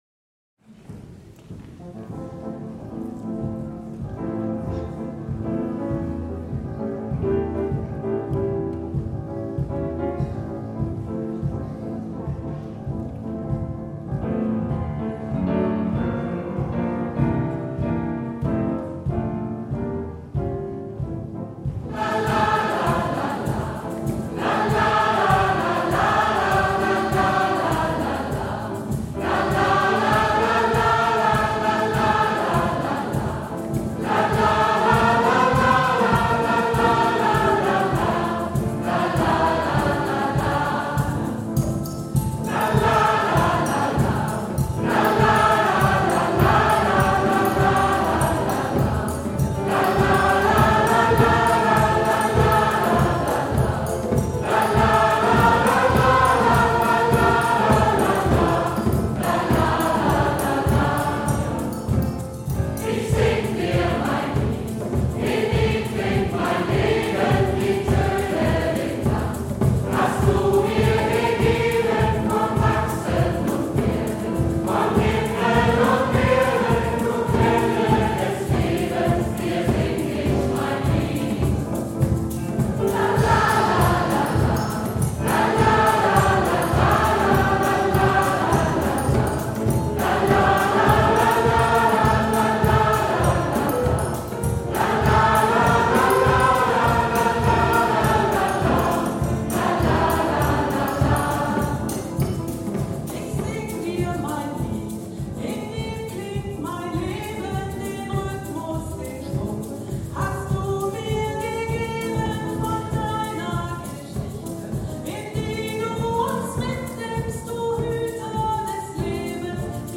Gospelchor - Evangelische Kirche Bad Neuenahr
Das Repertoire umfasst neben klassischer Gospelmusik auch die Bereiche Jazz und Pop.